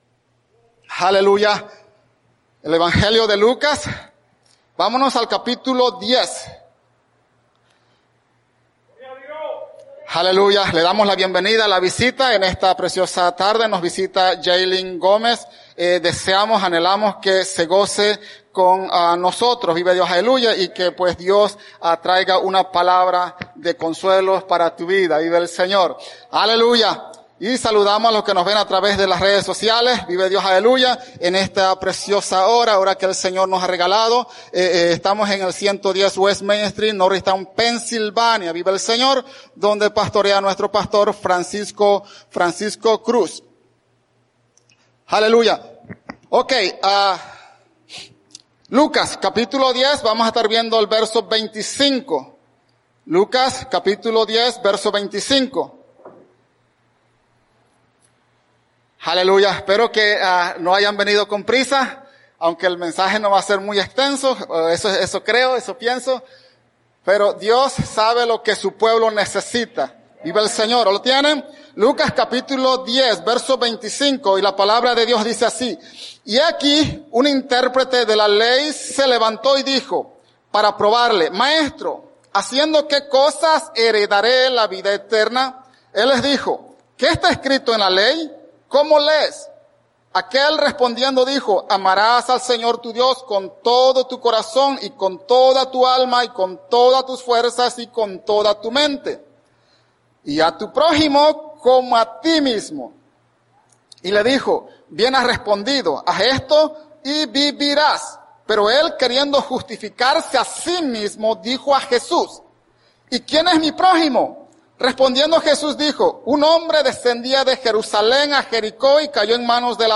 Predica
@ Norristown, PA